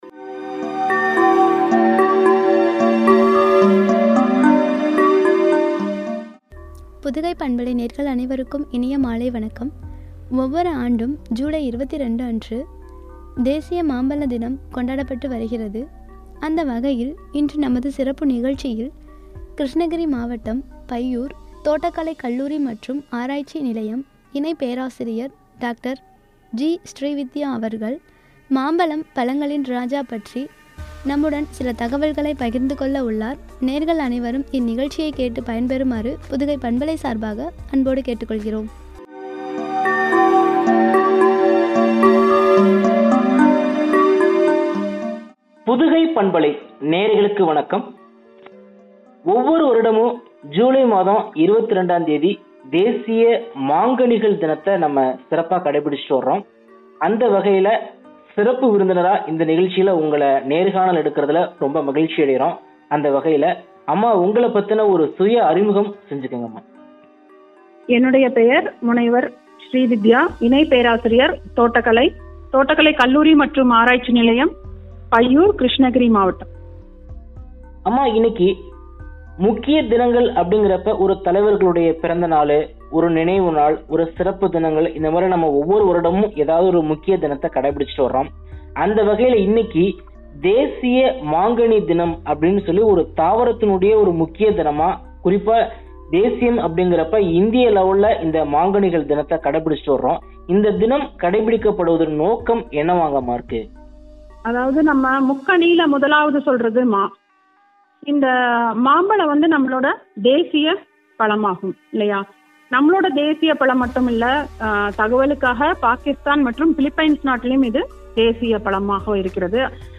பழங்களின் ராஜா” எனும் தலைப்பில் வழங்கிய உரையாடல்.